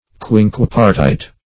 Quinquepartite \Quin*quep"ar*tite\